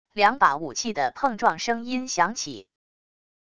两把武器的碰撞声音响起wav音频